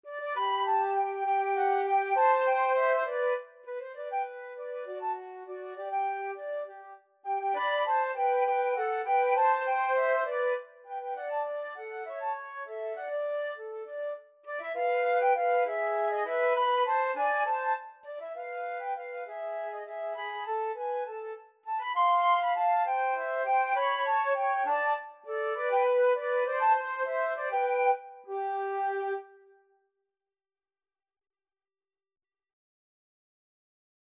3/8 (View more 3/8 Music)
Classical (View more Classical Flute Duet Music)